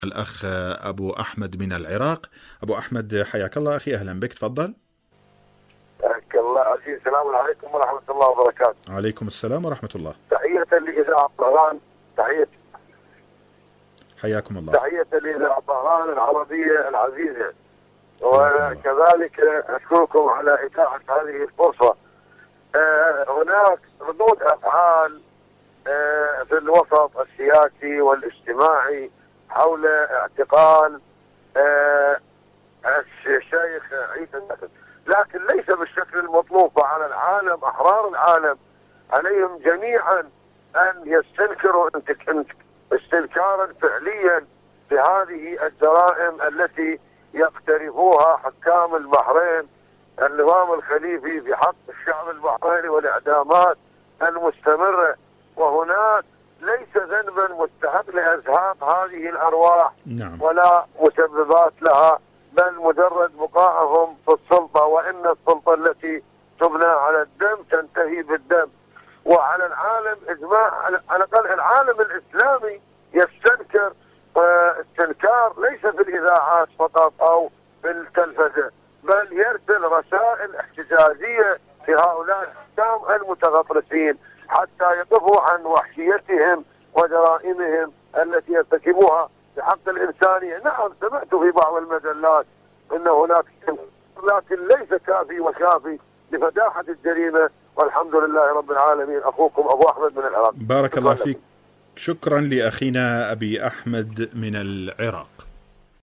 برنامج : بحرين الصمود / مشاركة هاتفية